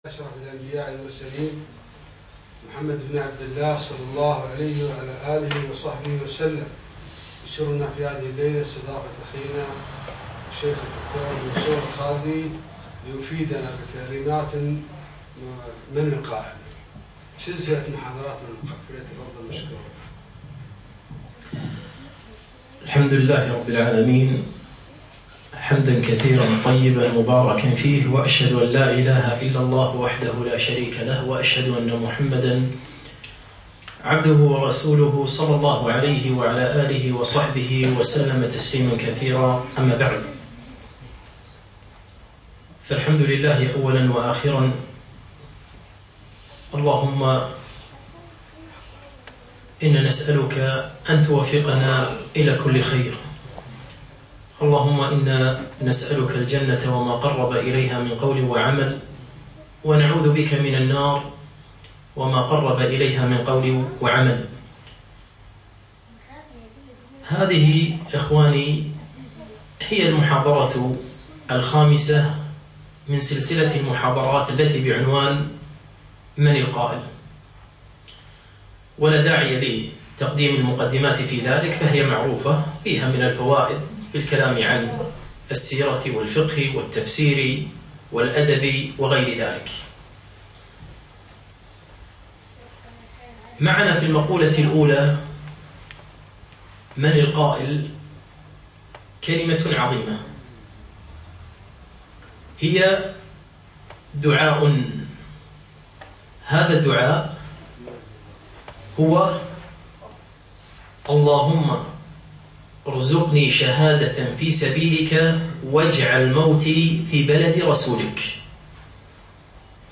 5- من القائل ؟ - المحاضرة الخامسة